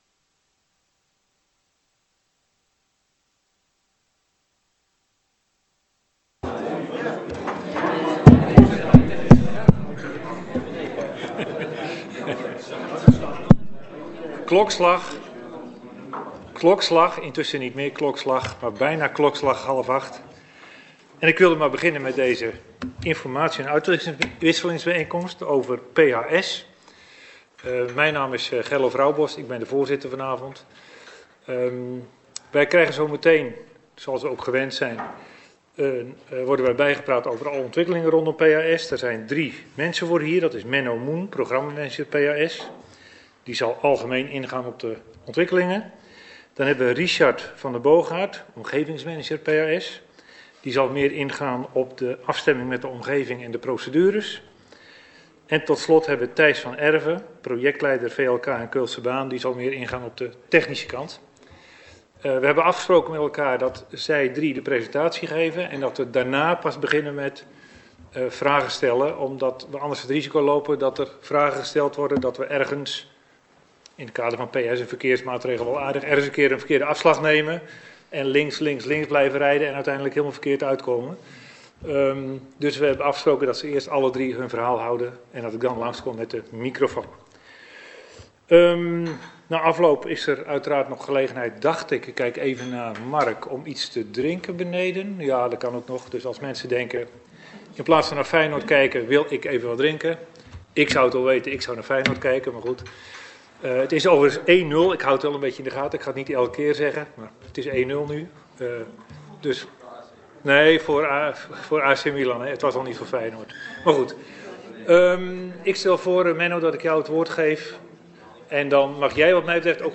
Locatie Raadzaal Boxtel Voorzitter Gerlof Roubos Toelichting Maatregelenpakket Programma Hoogfrequent Spoorvervoer (PHS) Agenda documenten Agendabundel 8 MB Geluidsopname Informatiebijeenkomst PHS 18 februari 2025 55 MB